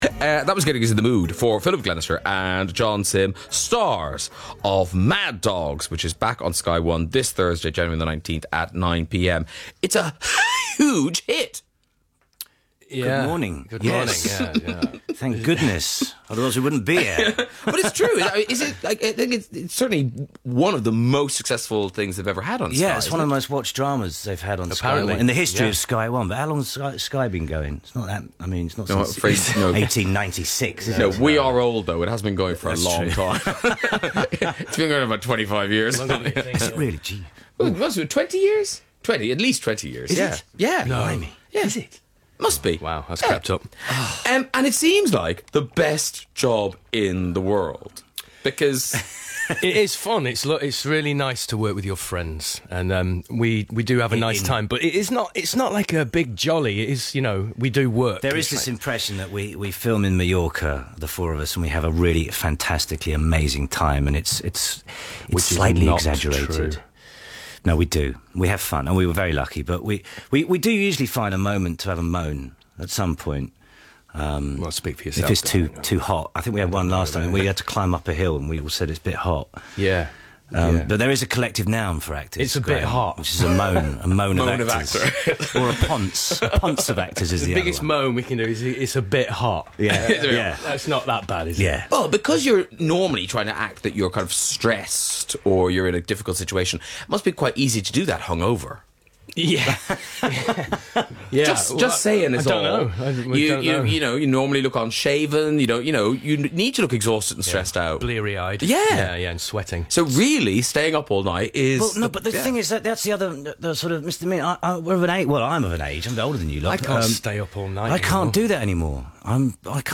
Radio Interview: John Simm & Philip Glenister join Graham Norton
Graham Norton BBC Radio 2 Broadcast 14 Jan 2012 There was a trio of great British actors joining Mr Norton this week. ...Philip Glenister and John Simm popped in to talk about Sky1's 'Mad Dogs' and the struggles of filming in stunning, hot locations.
graham-norton-john-simm-philip-glenister-bbc-radio-2.mp3